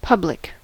public: Wikimedia Commons US English Pronunciations
En-us-public.WAV